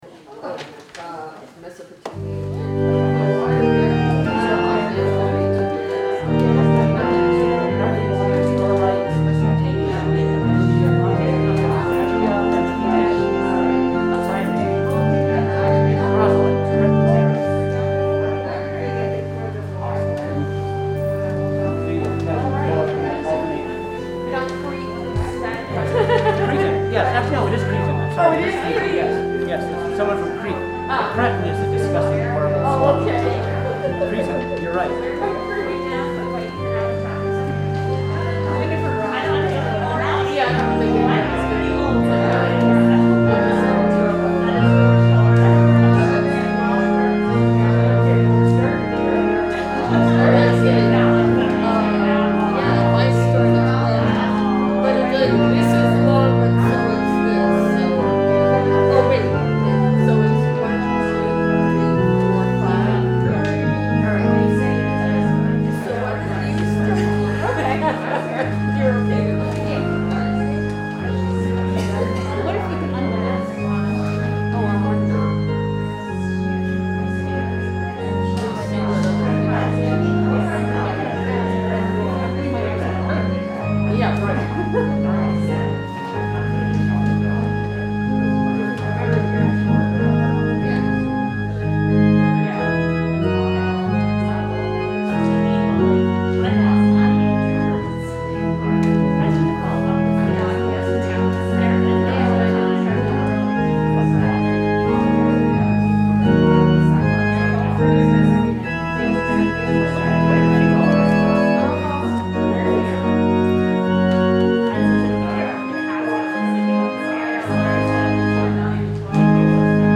We held worship on Sunday, May 23, 2021 at 10am!